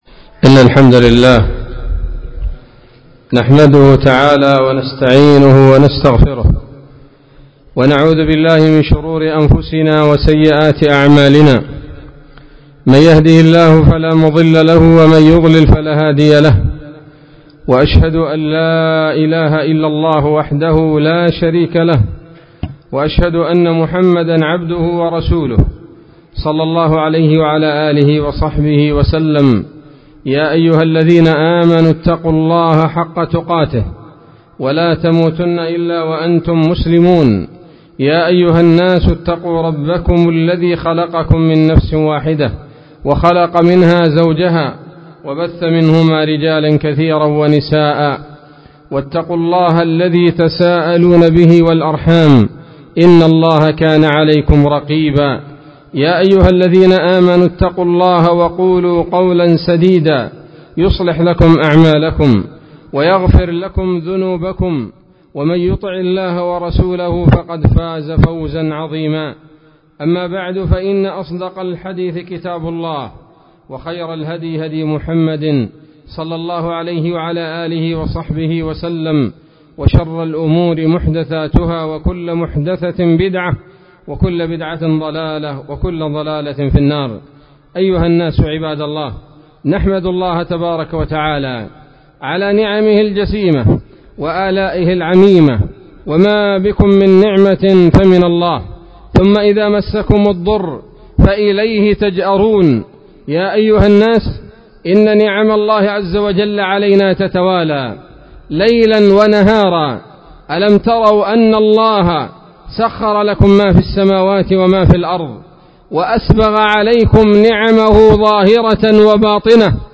خطبة-عيد-الأضحى-1444-هـ_دقة-عادية.mp3